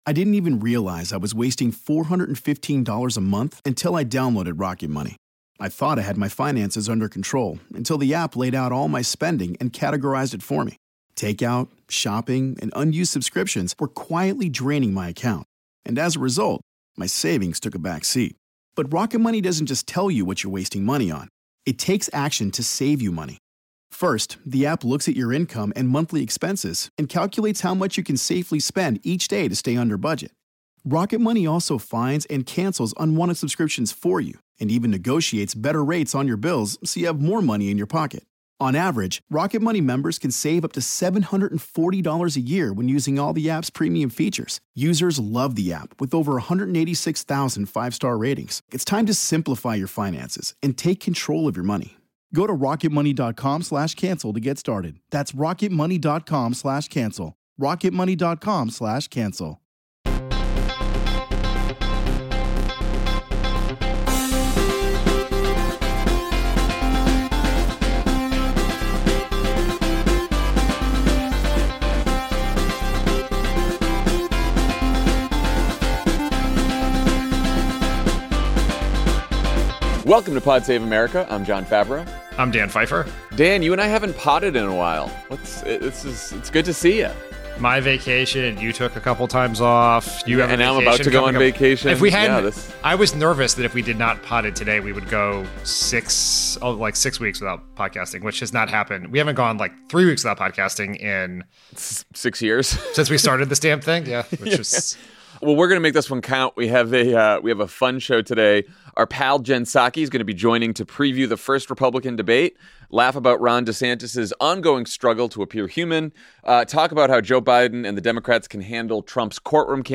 Donald Trump and his 18 co-defendants prepare to turn themselves in to Fulton County jail. Jen Psaki joins to preview the first debate, laugh about Ron DeSantis’s ongoing struggle to appear human, and talk about how Joe Biden and Democrats should handle Trump’s courtroom campaign. And later, Jon, Dan, and Jen play a round of 2 Takes and a Fake.